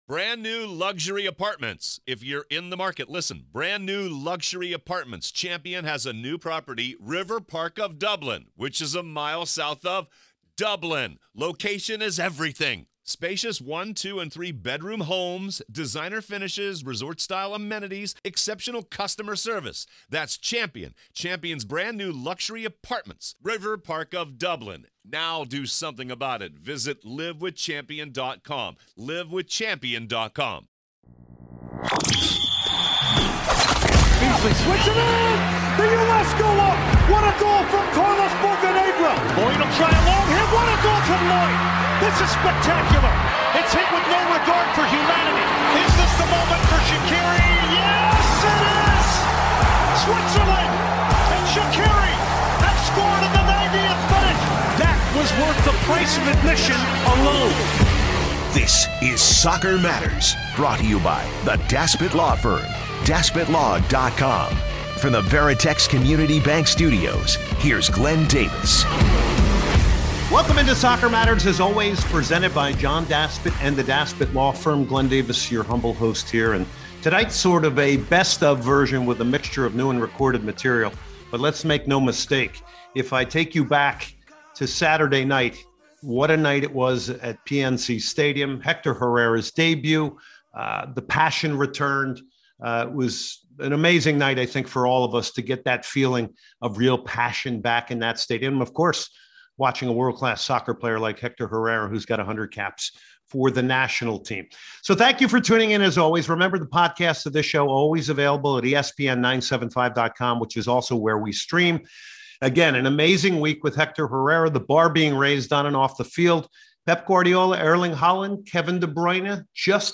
for a fresh interview discussing his career and looking ahead to the upcoming Houston friendly vs Club America.